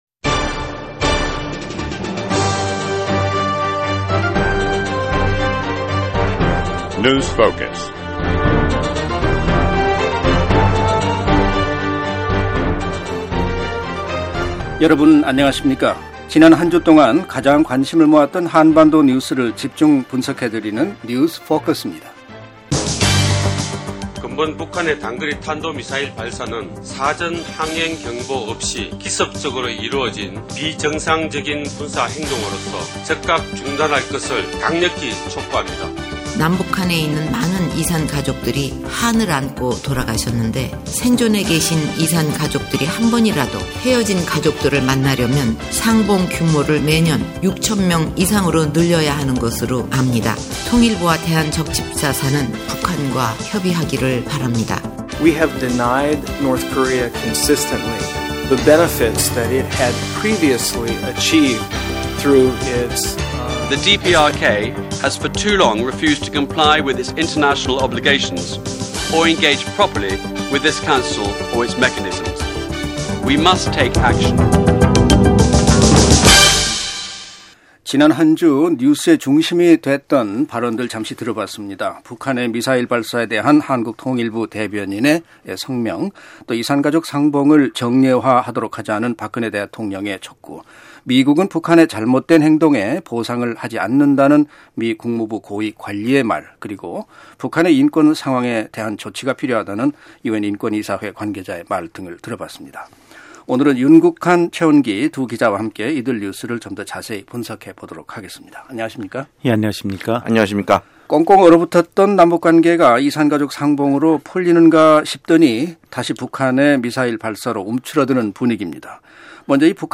지난 한주 동안 가장 관심을 모았던 한반도 뉴스를 집중 분석해드리는 뉴스 포커스입니다. 오늘은 북한의 미사일 발사, 대니얼 러셀 국무부 차관보의 발언, 그리고 남북 이산가족 상봉 문제와 북한 인권 보고서에 대해 살펴보았습니다.